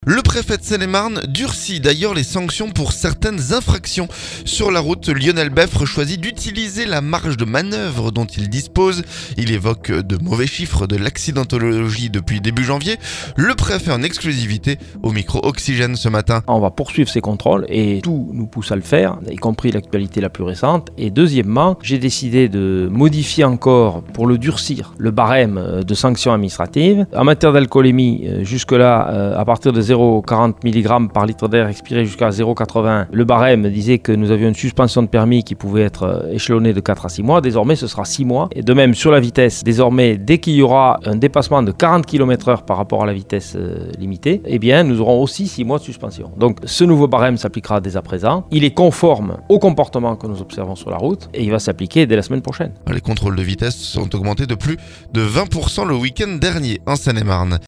Le préfet en exclusivité au micro Oxygène ce vendredi.